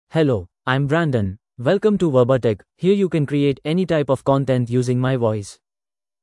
Brandon — Male English (India) AI Voice | TTS, Voice Cloning & Video | Verbatik AI
Brandon is a male AI voice for English (India).
Voice sample
Male
English (India)
Brandon delivers clear pronunciation with authentic India English intonation, making your content sound professionally produced.